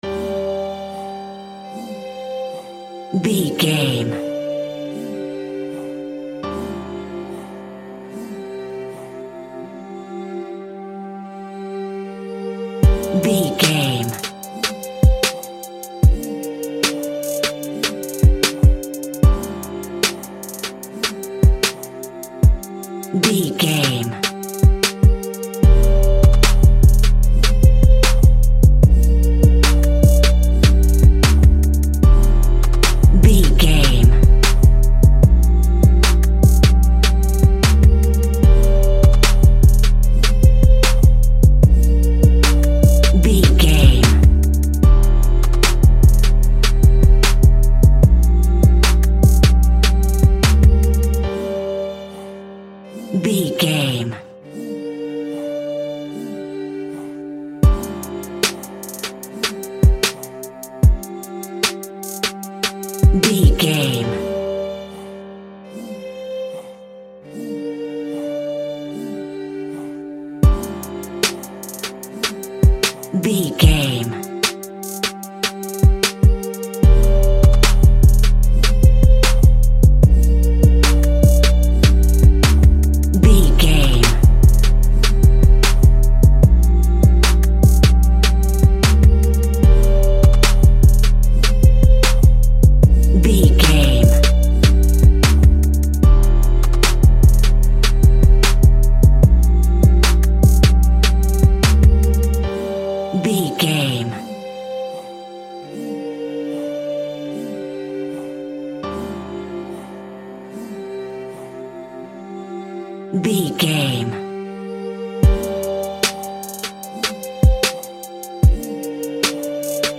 Ionian/Major
drums
smooth
calm
mellow